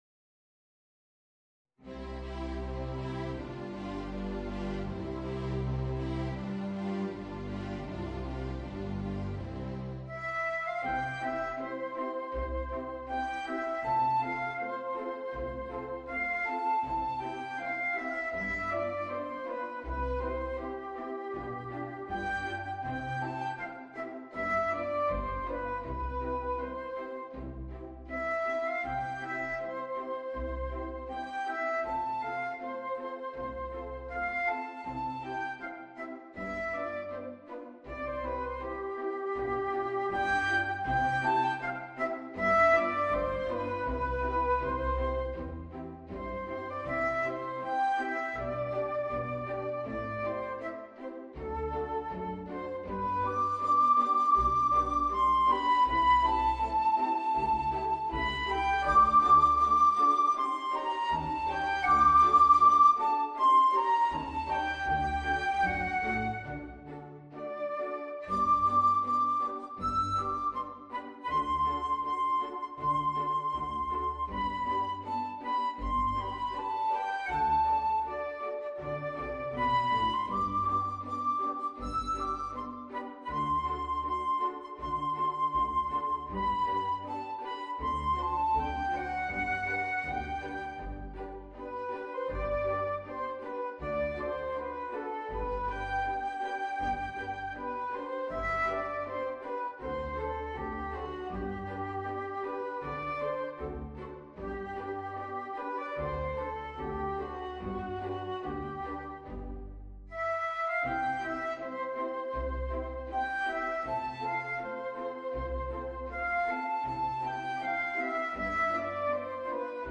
Voicing: String Quintet